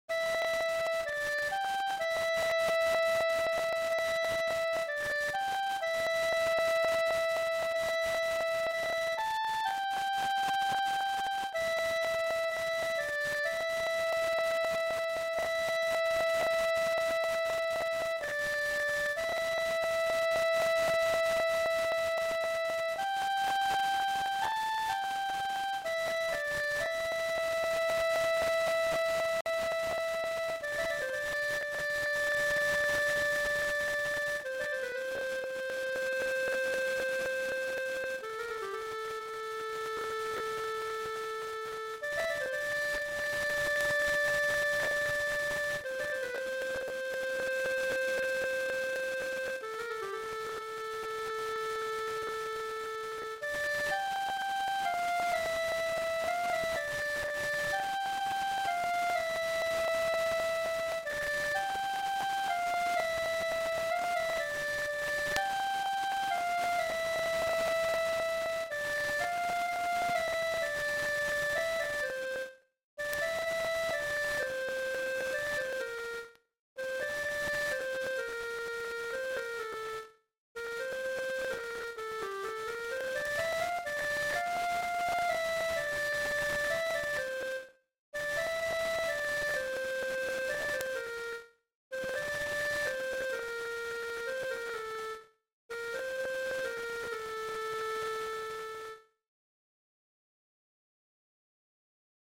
ساکسوفون
تنظیم شده برای ایام سوگواری حسینی
تنظیم اصلی برای ساکسوفون